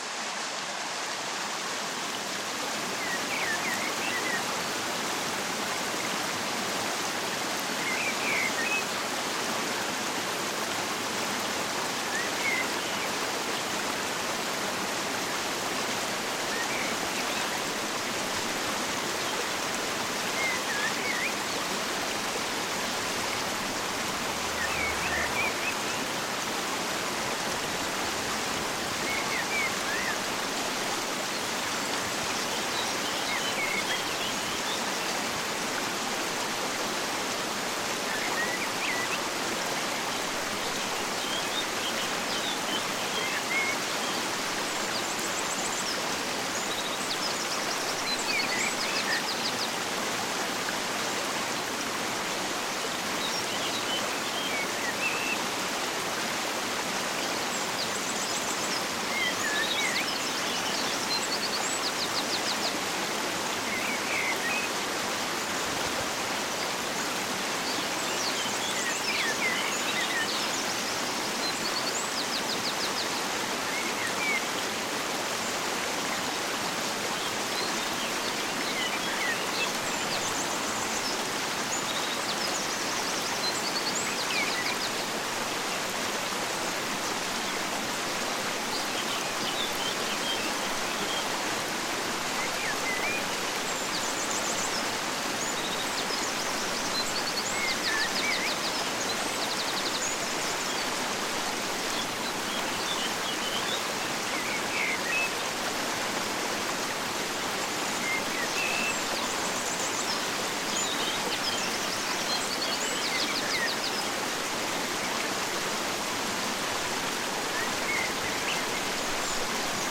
HERZ-FRIEDEN-TRÄGER: Bergflöte-Wind mit Anden-Stimmen